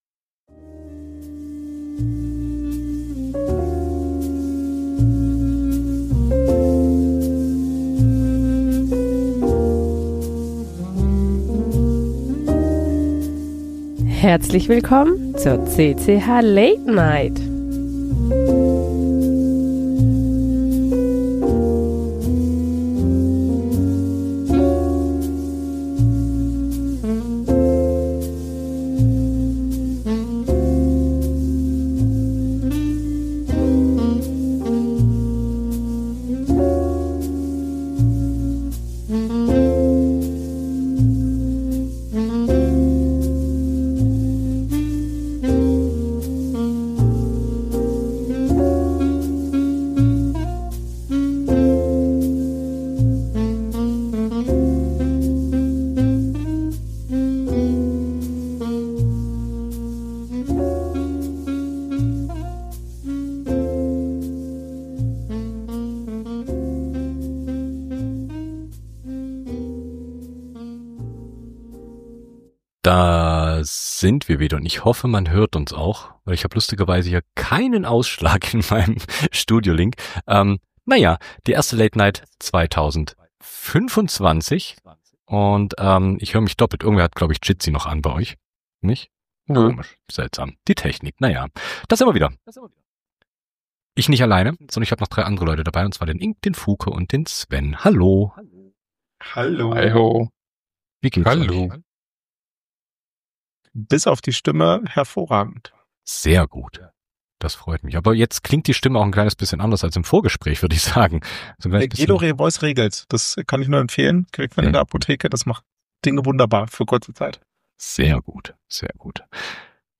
Die CCH! Late Night! ist eure monatliche Live-Keyboard-Late-Night-Show.